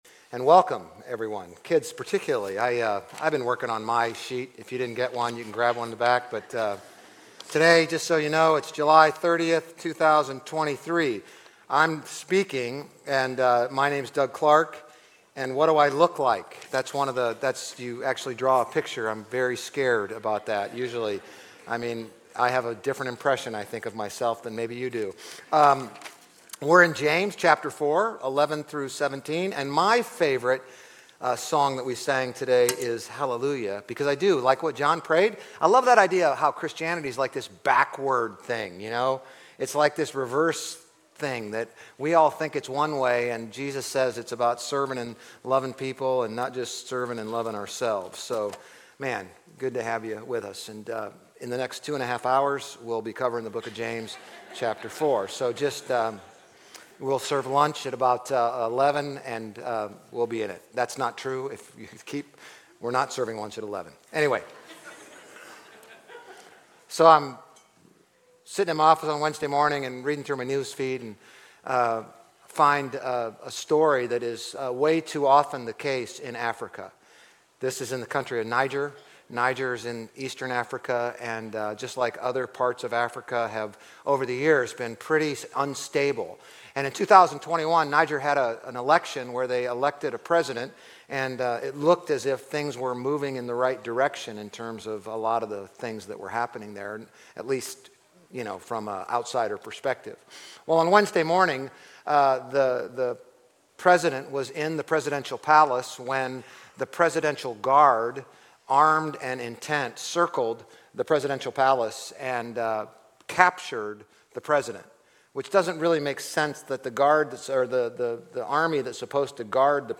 GCC-OJ-July-30-Sermon.mp3